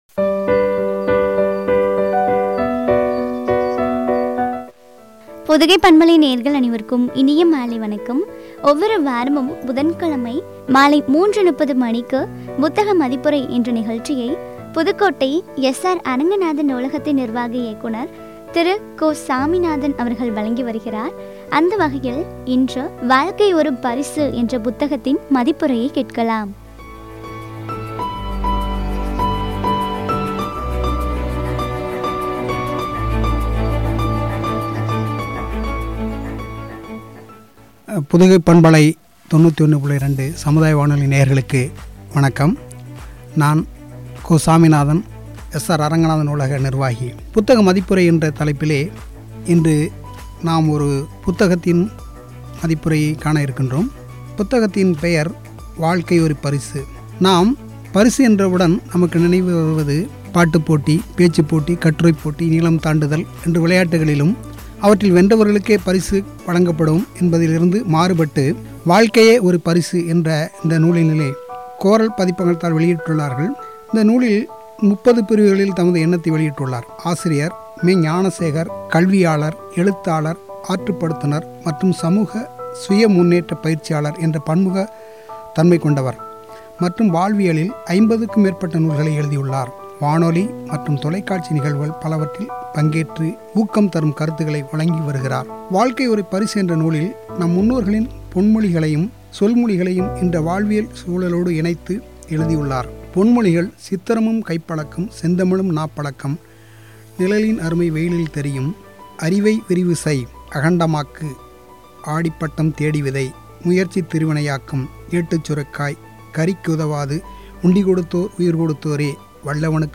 குறித்த வழங்கிய உரையாடல்.